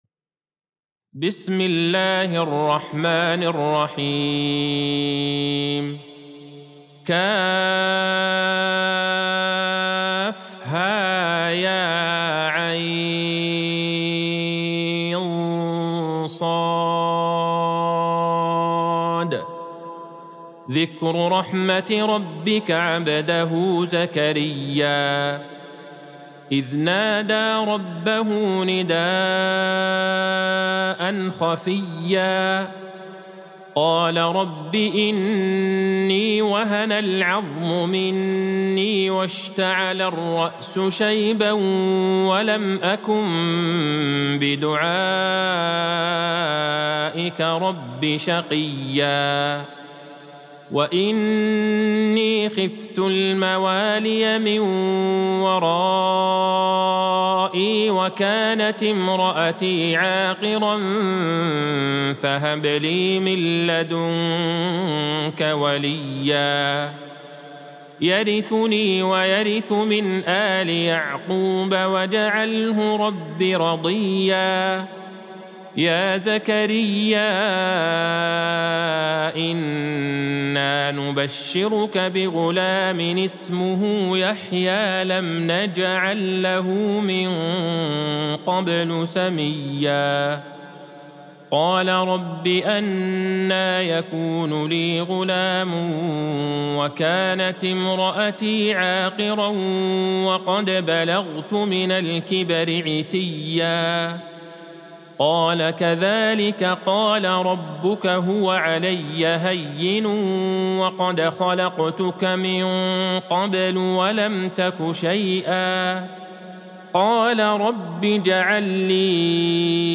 سورة مريم Surat Maryam | مصحف المقارئ القرآنية > الختمة المرتلة